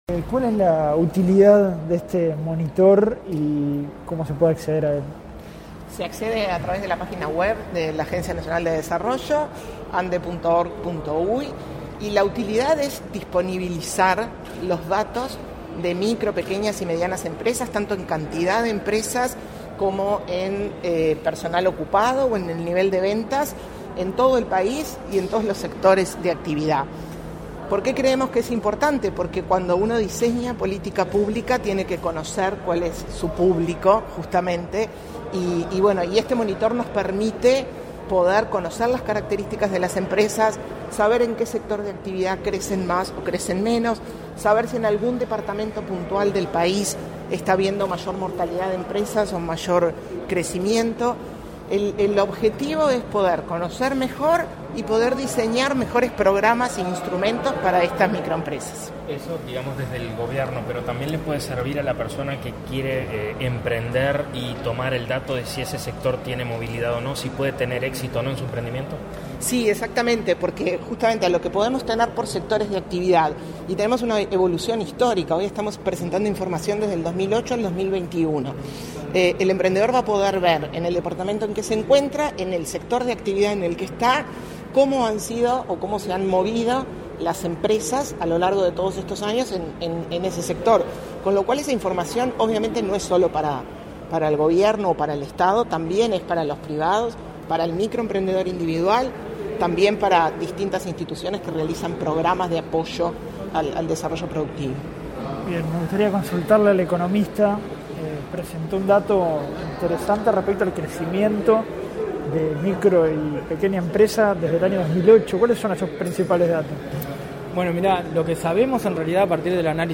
Declaraciones a la prensa de la presidenta de ANDE